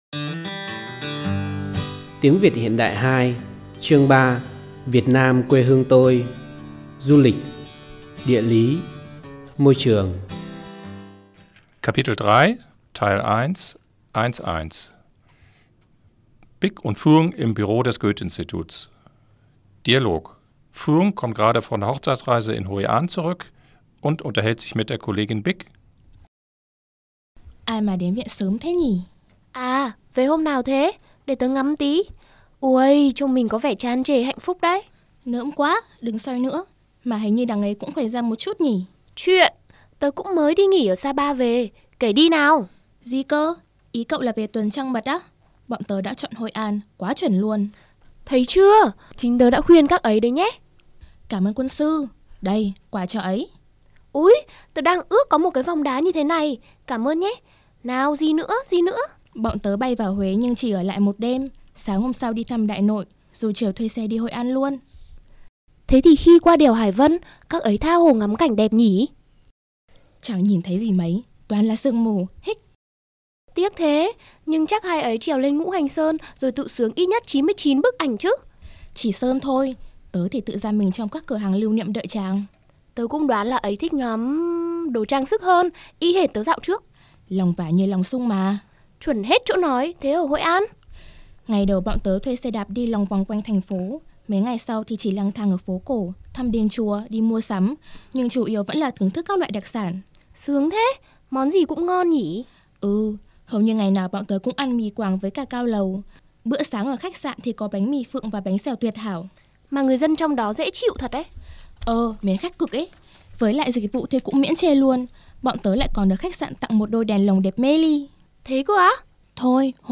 Die Tonaufnahmen wurden wie beim ersten Lehrbuch von sechs Muttersprachlern, die alle Standardvietnamesisch sprechen, im Tonstudio der Universität für Sozial- und Geisteswissenschaften Hanoi aufgenommen.